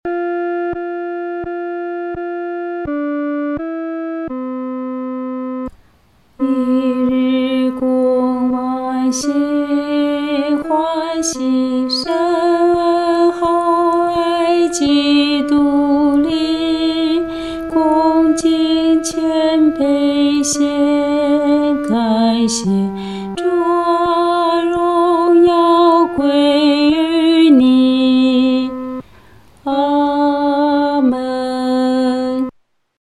女低
它的进行简单朴素，所表达的是对神真诚的依赖与信靠。